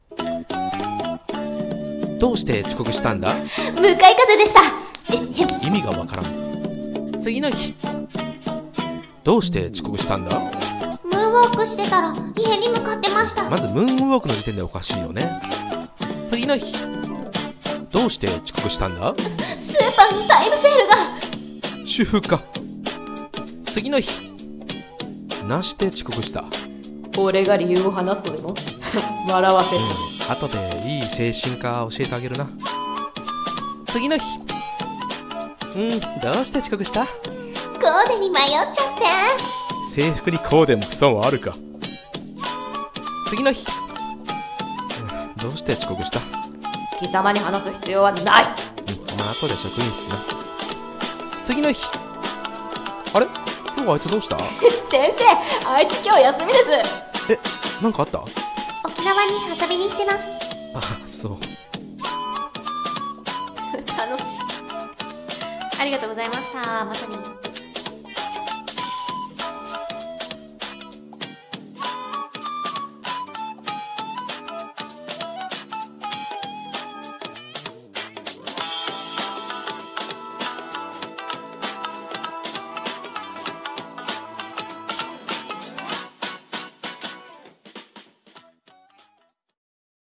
【声劇コラボ】声劇「遅刻の理由」